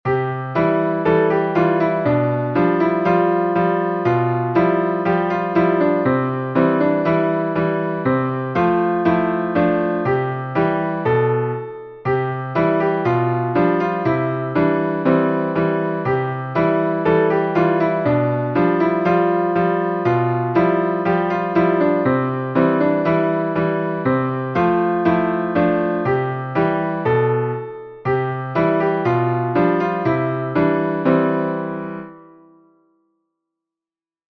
Piyano Notaları MP3